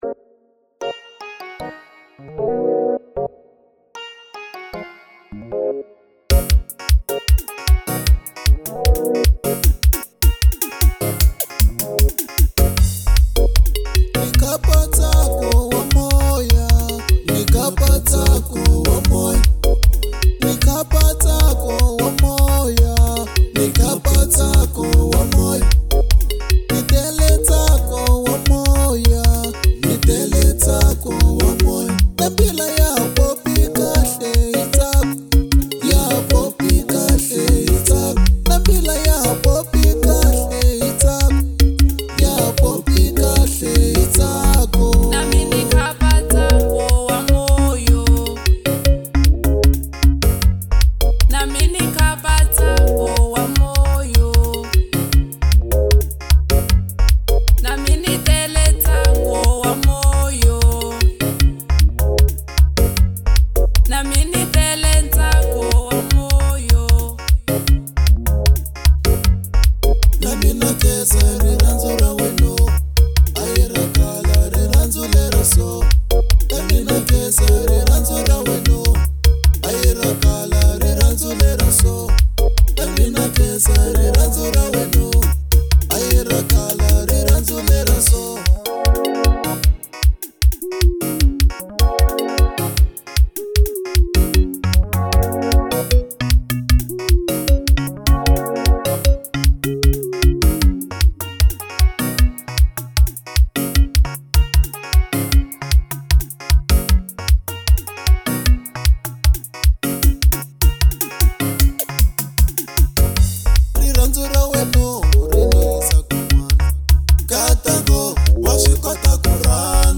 05:33 Genre : Xitsonga Size